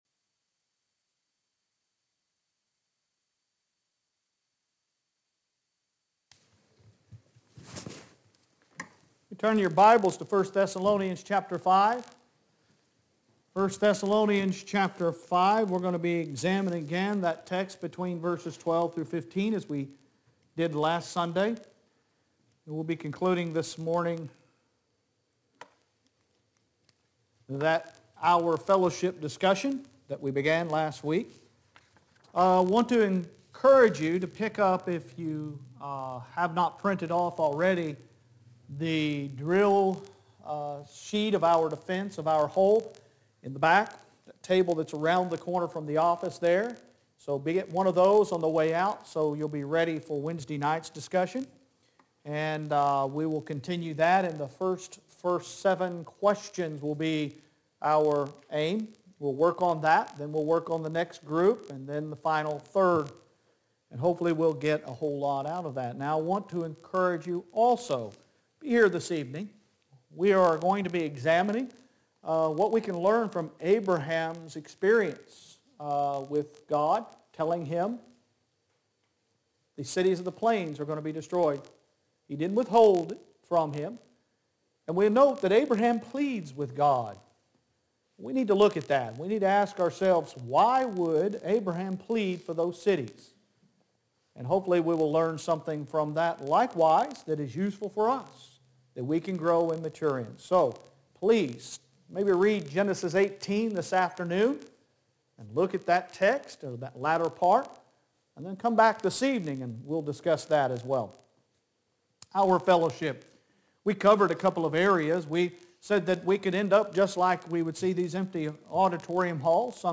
Sun AM Worship 11.07.21